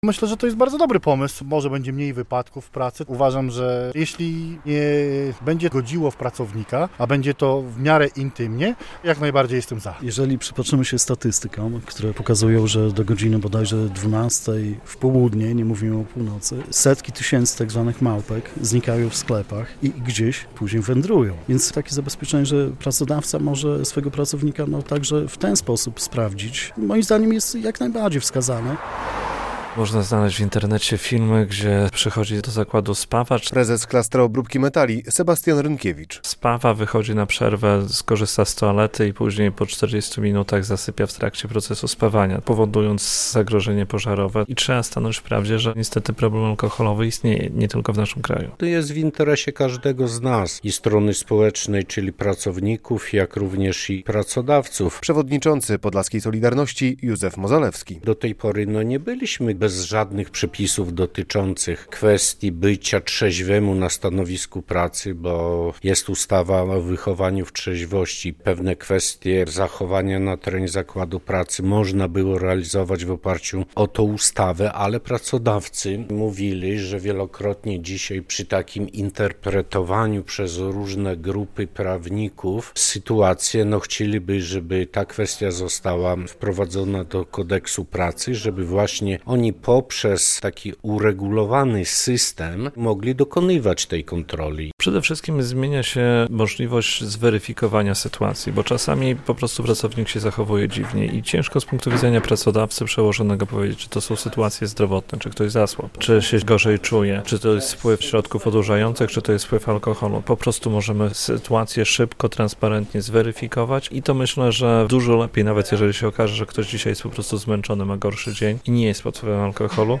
Kontrole trzeźwości pracowników - relacja